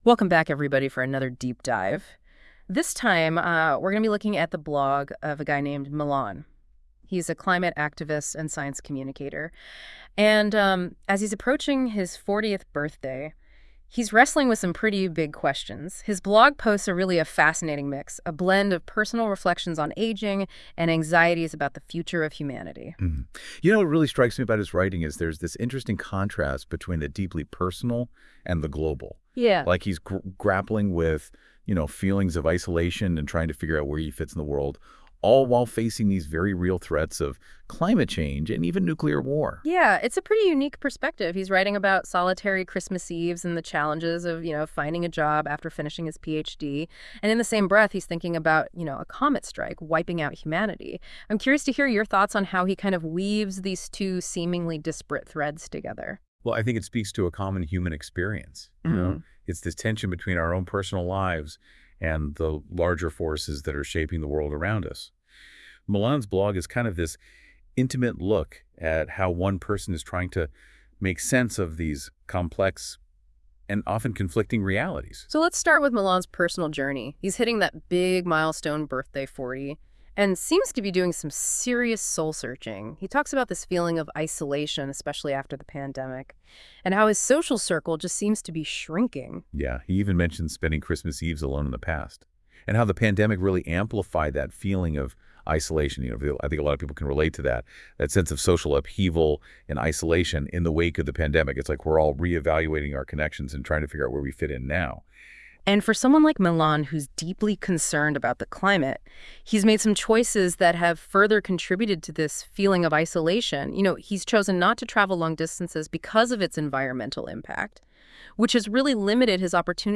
Here is NotebookLM’s default audio overview of my last two birthday posts, with no additional user prompting.